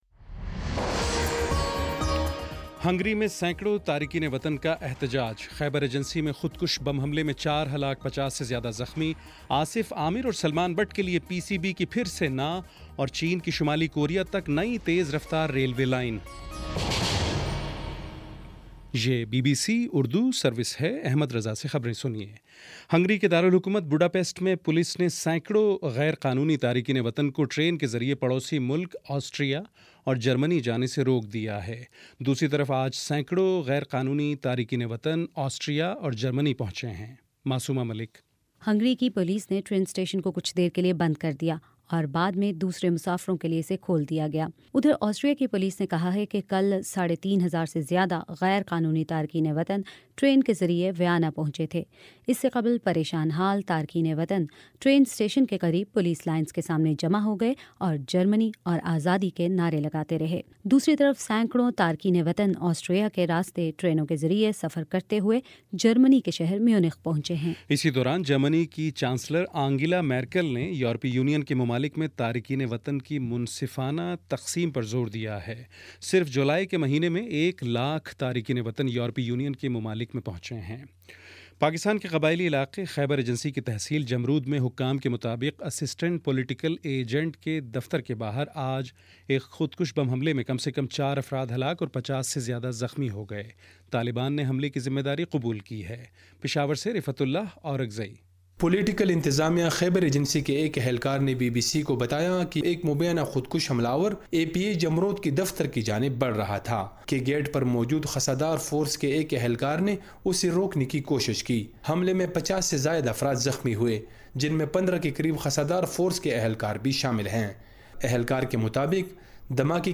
ستمبر 1: شام سات بجے کا نیوز بُلیٹن